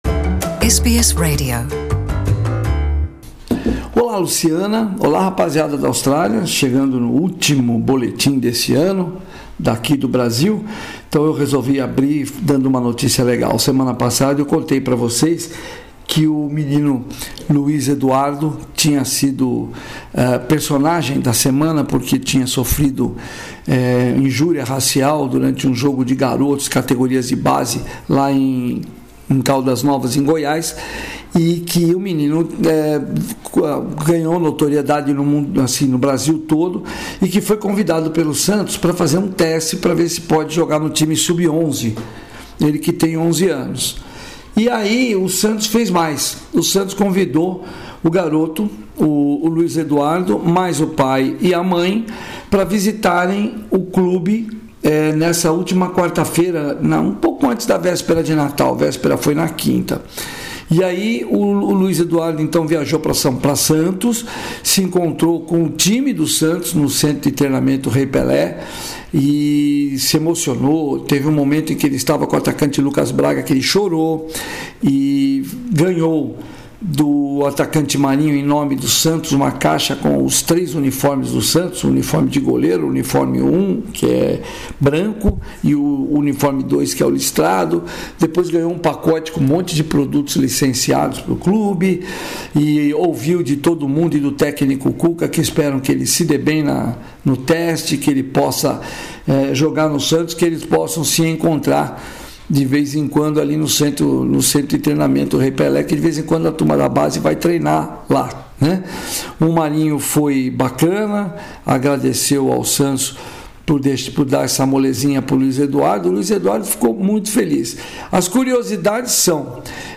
Estas e outras notícias do esporte brasileiro na semana no boletim acima.